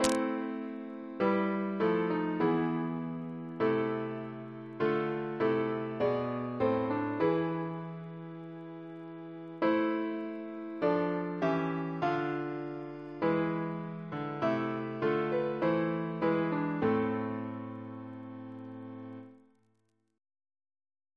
Double chant in A minor Composer: William Beale (1784-1854) Reference psalters: ACP: 129